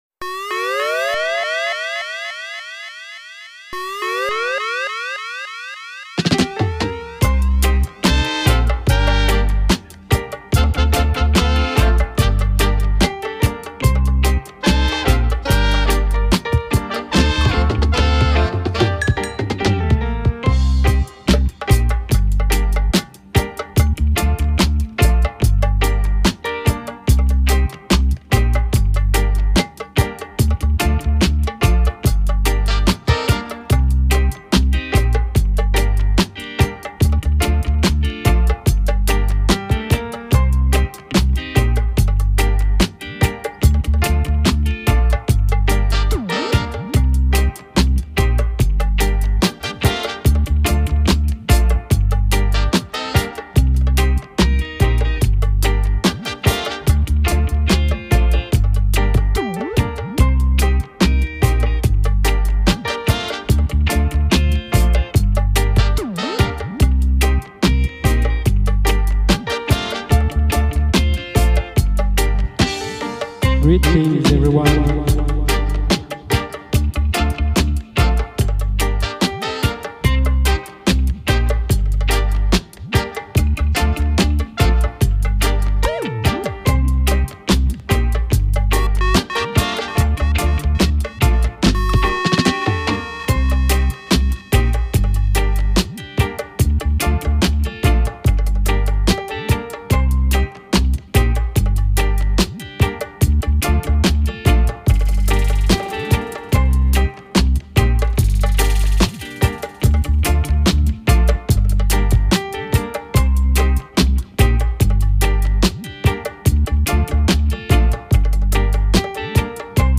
UK Roots - Steppas